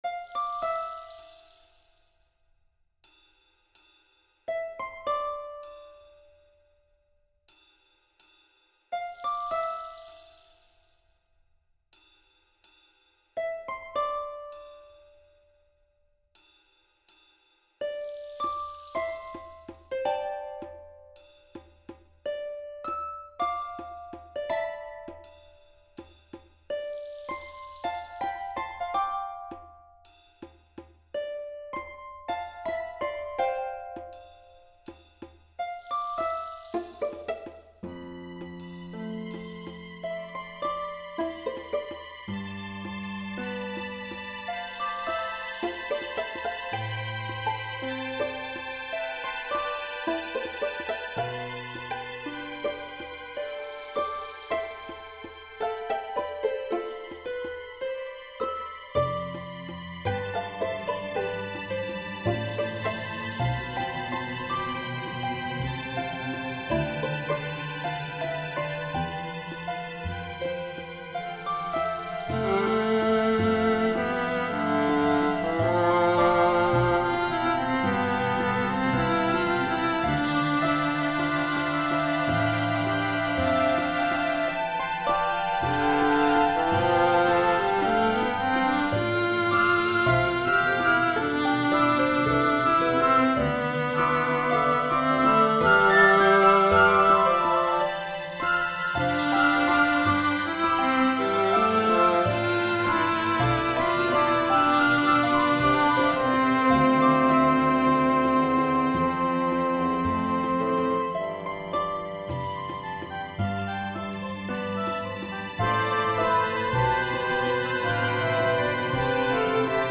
This movement comes from my love of birdsong as I remember leisurely walking through an aviary for the first time years ago. It is intentionally more atmospheric than melodic in nature. The upper strings and woodwinds represent various bird noises while the lowers offer nature’s foundation of soil, plants, and trees. The harp is the breeze and dewy atmosphere through which the violist strolls, basking in the sensory elements of the space.
IV. Aviary (MIDI-rendered recording)